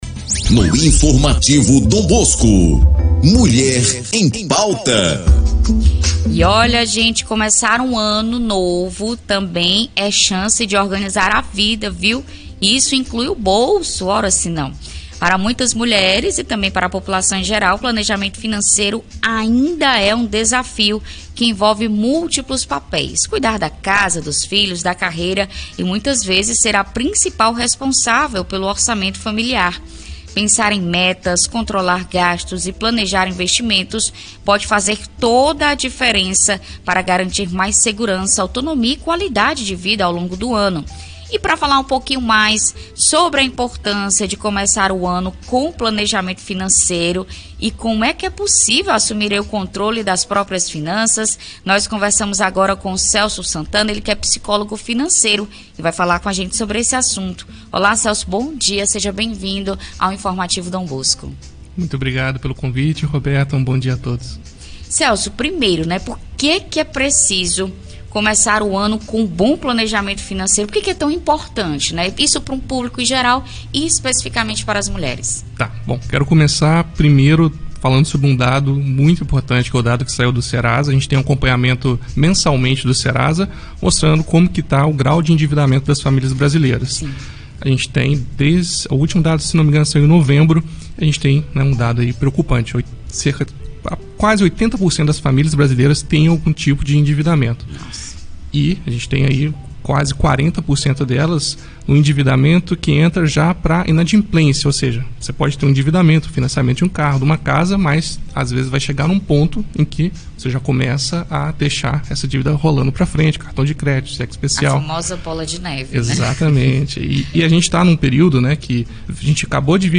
ENTREVISTA-0701.mp3